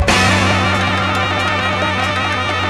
HORN WAVER00.wav